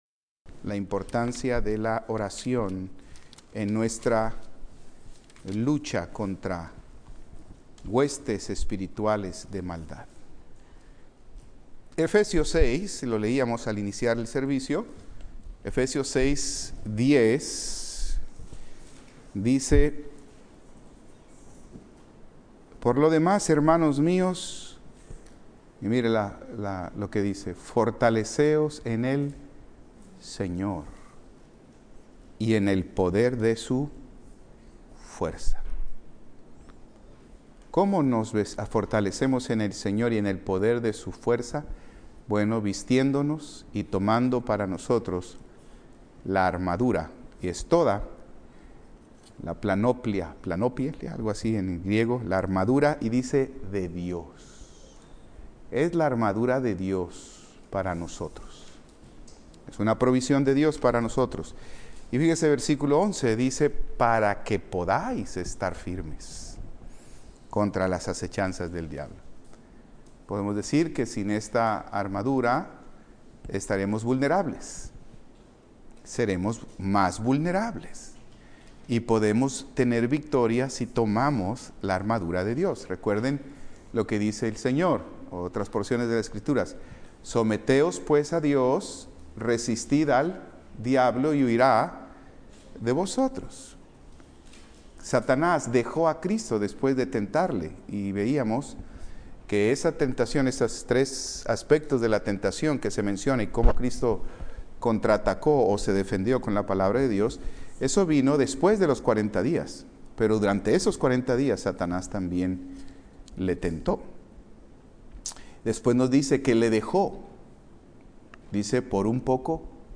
Servicio Vespertino -